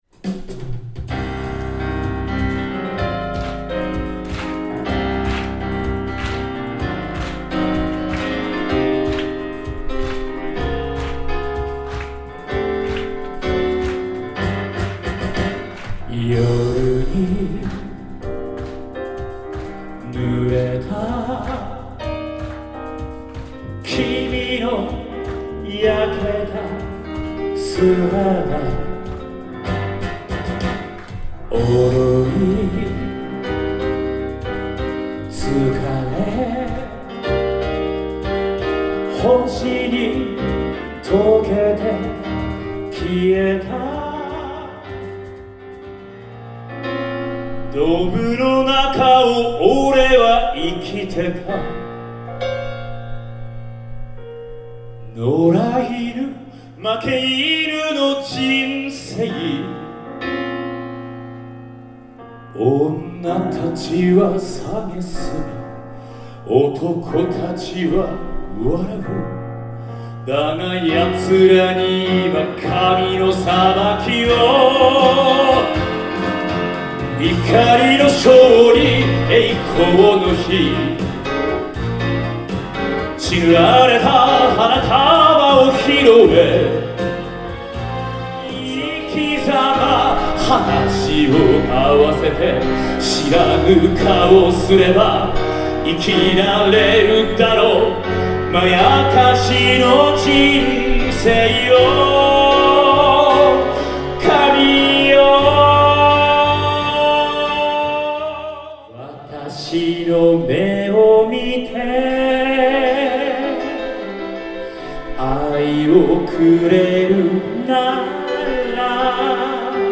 イヴイヴのクリスマスコンサートにお運びいただいた皆様、ありがとうございました。